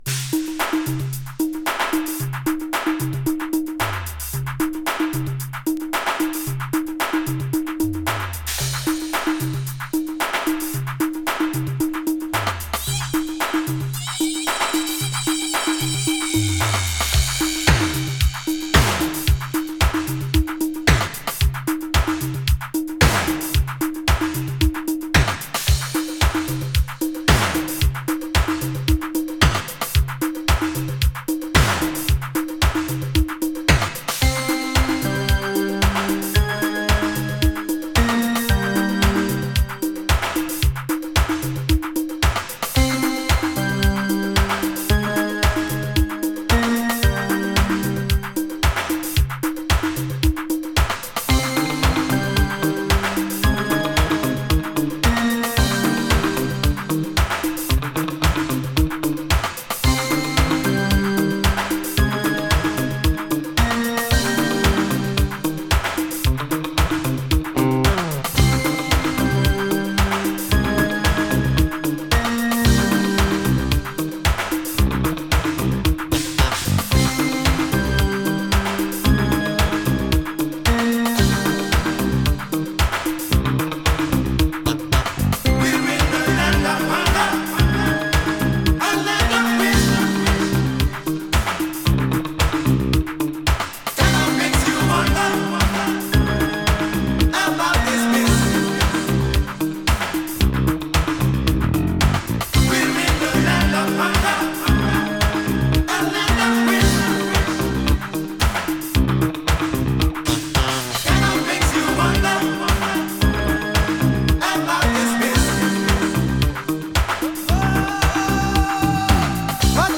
New Wave Disco!
【NEW WAVE】